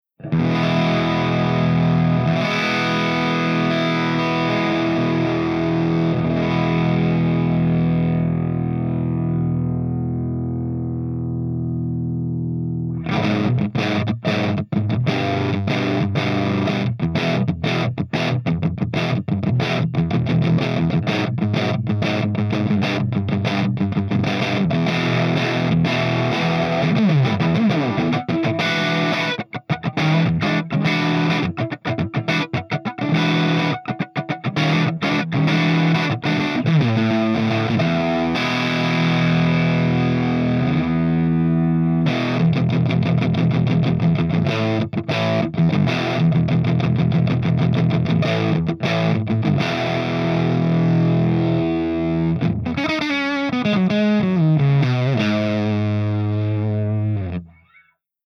117_MARSHALLJCM800_CH1HIGHGAIN_GB_SC